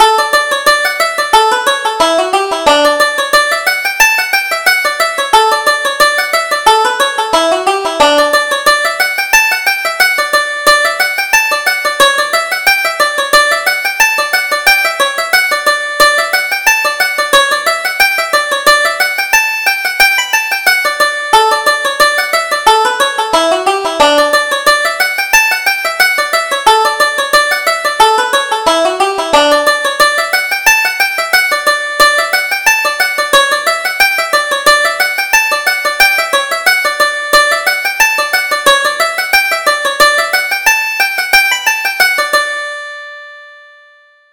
Reel: Corney Is Coming